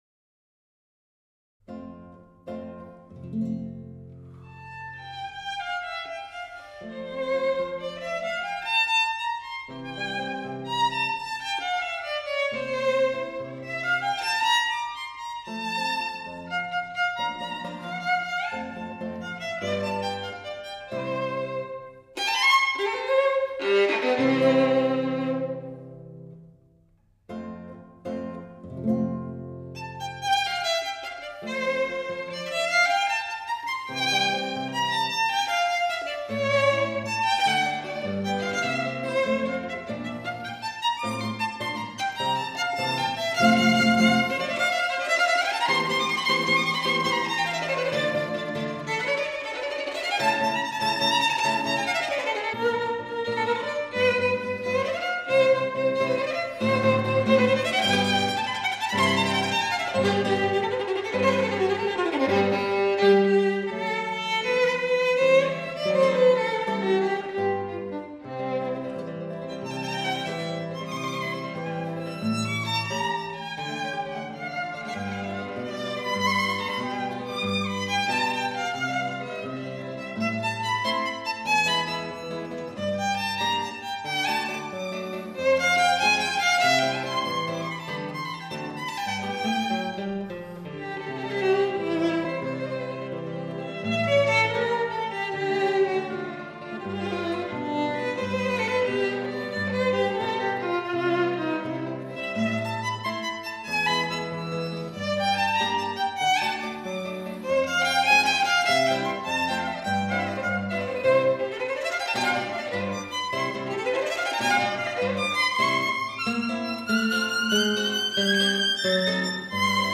室内乐
琴音饱满充满光泽，能量充足却绝对不会刺耳；残响丰富无比，细节丰富